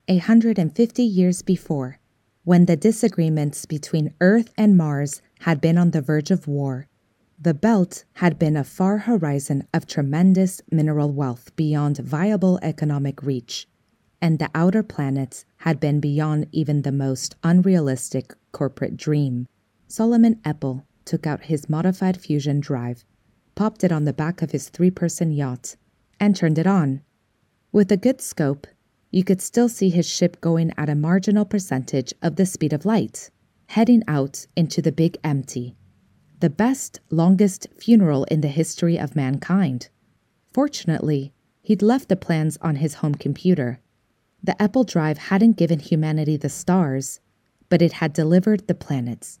Sci-Fi, Narrative
Voix off Comédienne